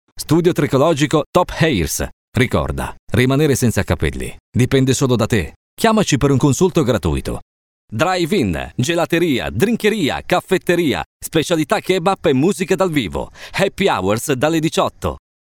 Voce maschile dinamica, brillante e versatile per comunicati rivolti sia ad un target giovane che meno giovane, si presta perfettamente per ogni tipo di comunicato.
Sprechprobe: Werbung (Muttersprache):